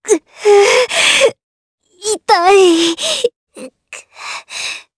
Leo-Vox_Dead_jp.wav